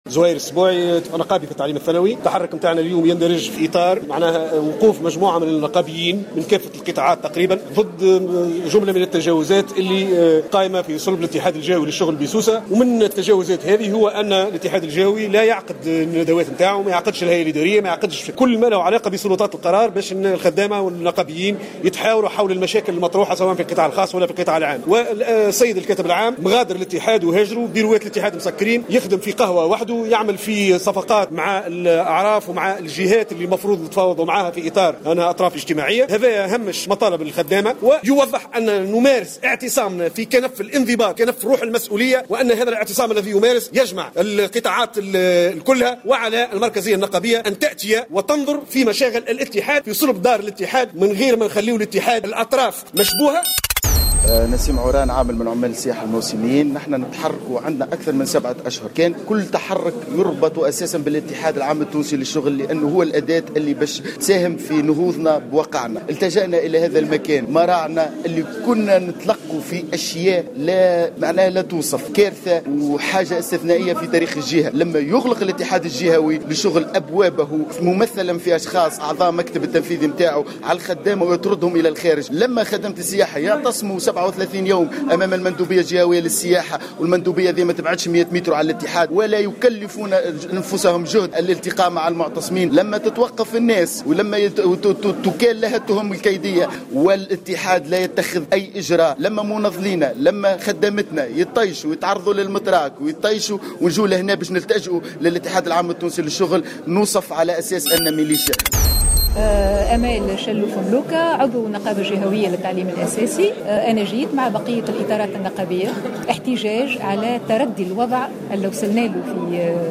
روبرتاج